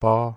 고성조 (High)pobpoz
몽어 pob 발음